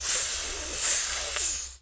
snake_hiss_12.wav